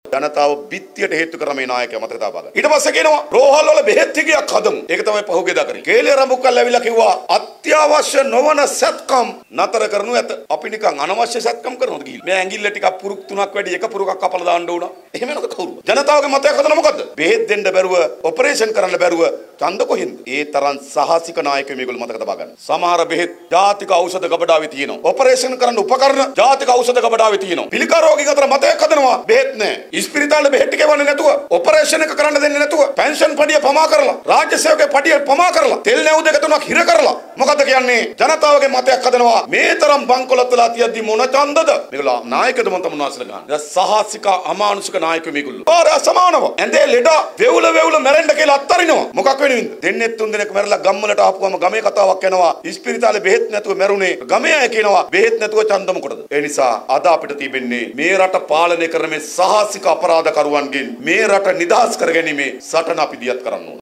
බදුල්ල ප්‍රදේශයේ පැවති ජන රැලියකට එක් වෙමින් ජාතික ජනබලවේගයේ නායක අනුර කුමාර දිසානායක මහතා ද පළාත් පාලන මැතිවරණය සම්බන්ධයෙන් මෙලෙස අදහස් ප්‍රකාශ කළා.